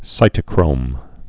(sītə-krōm)